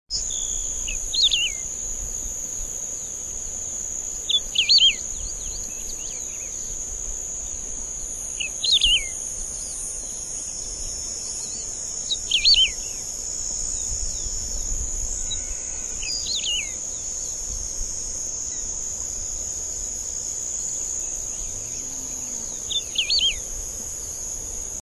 Coludo Grande (Emberizoides herbicola)
Nombre en inglés: Wedge-tailed Grass Finch
Fase de la vida: Adulto
Localidad o área protegida: Lunarejo
Condición: Silvestre
Certeza: Fotografiada, Vocalización Grabada